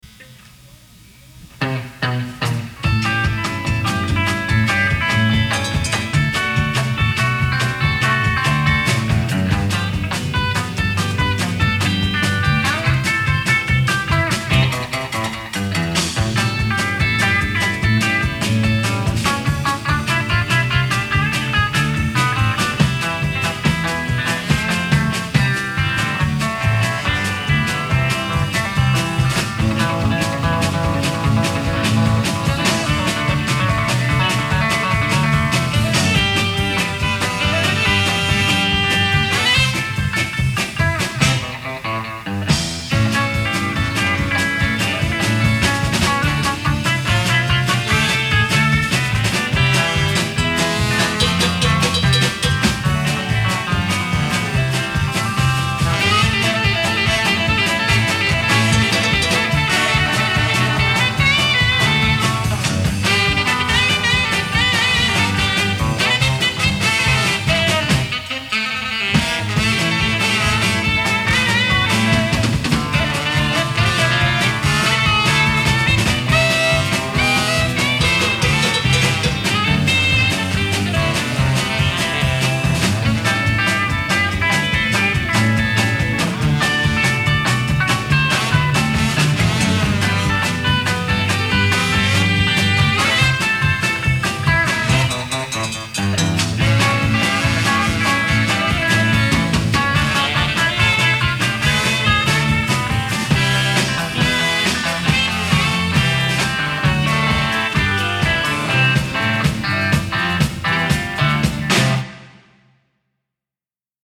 компиляции из песен в живом исполнении